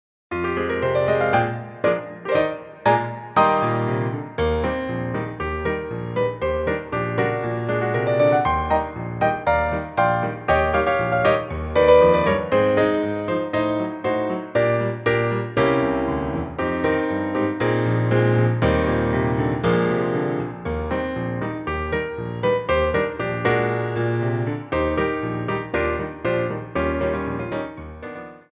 Grand Allegro 2 / Coda
2/4 (16x8)